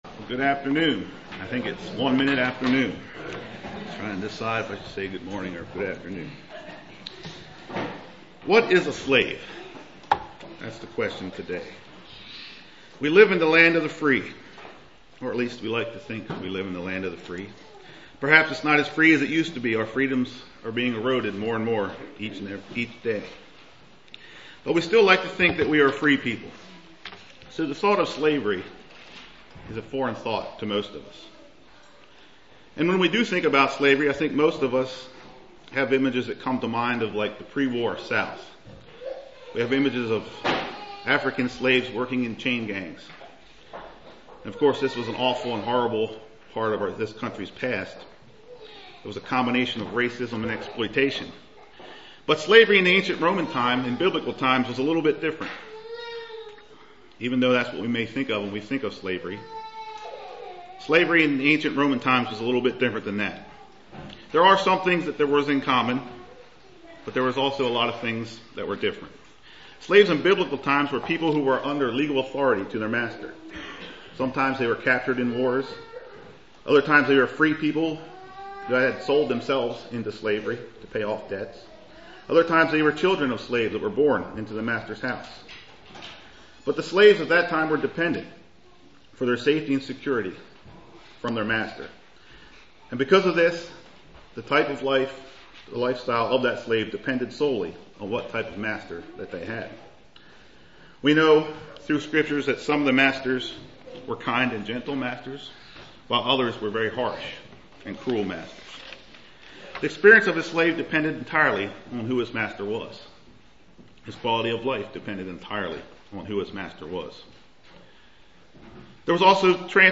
Given in Lewistown, PA
UCG Sermon Studying the bible?